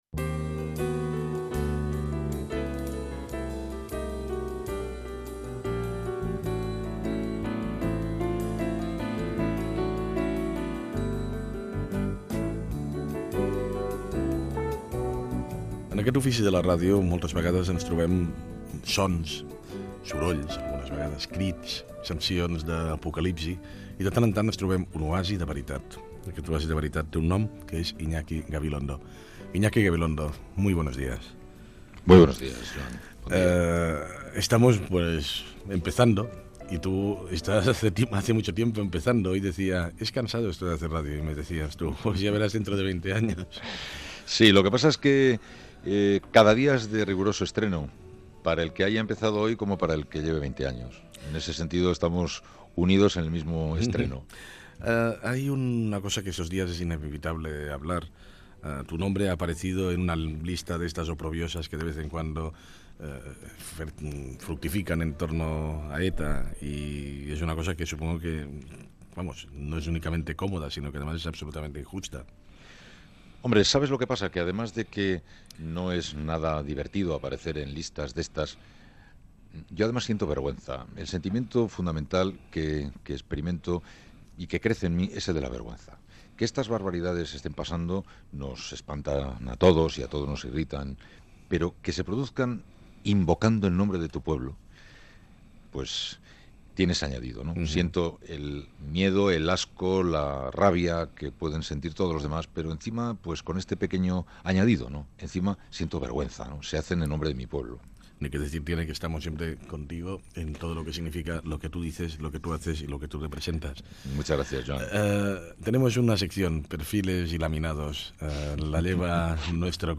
Fragment d'una entrevista al periodista Iñaki Gabilondo.
Info-entreteniment